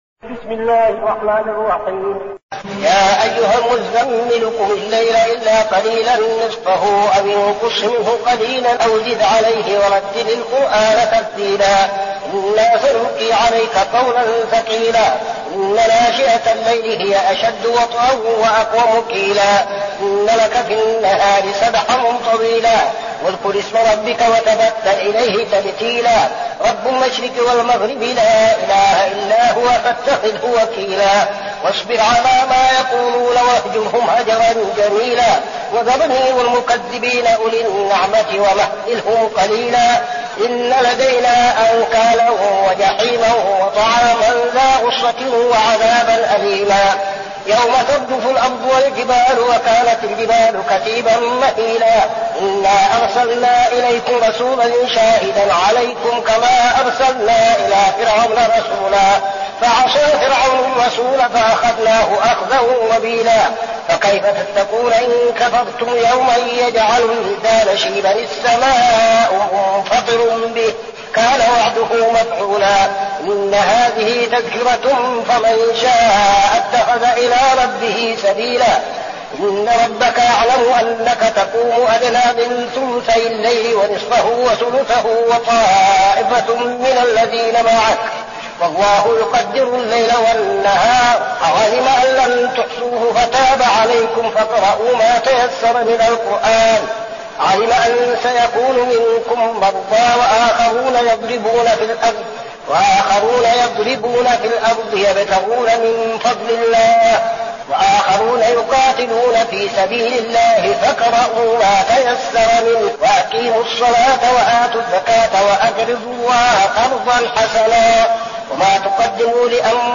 المكان: المسجد النبوي الشيخ: فضيلة الشيخ عبدالعزيز بن صالح فضيلة الشيخ عبدالعزيز بن صالح المزمل